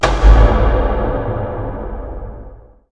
collision_black.wav